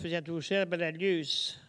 Localisation Soullans
Langue Maraîchin
Catégorie Locution